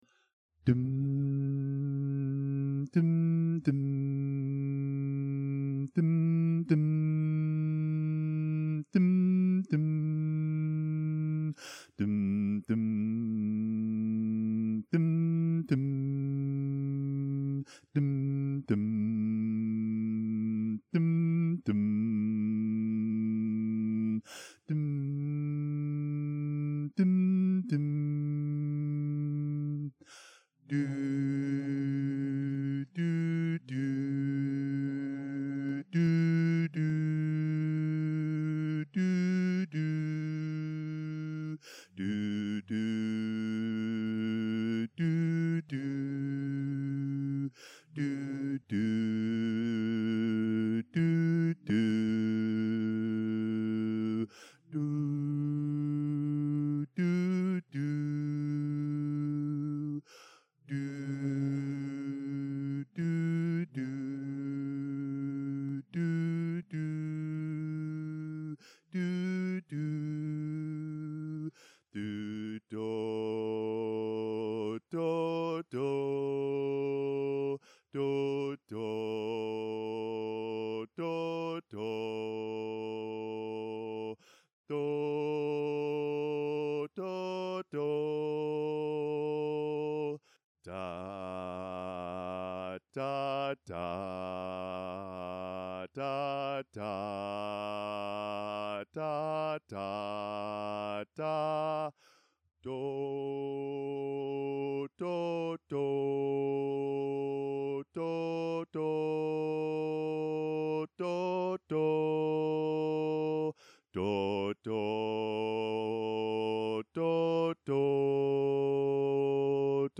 Bari Instrumental